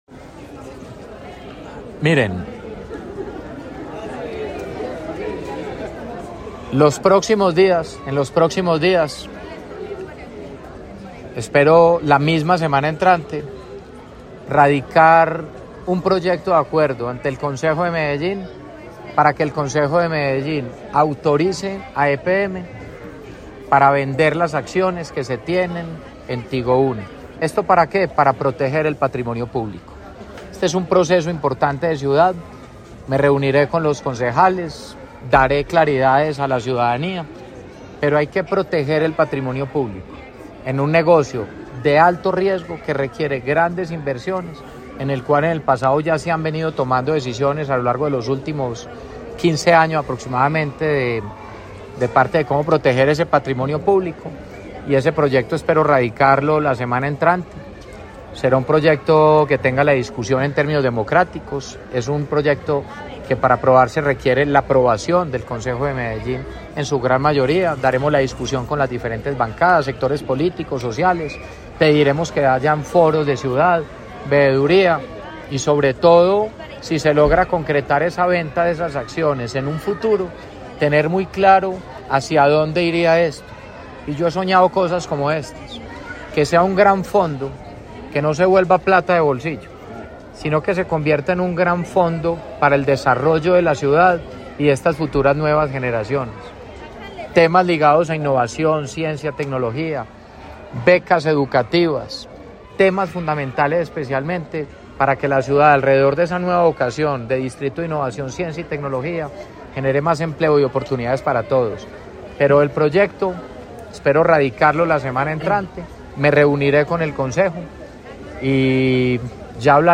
Palabras de Federico Gutiérrez Zuluaga, alcalde de Medellín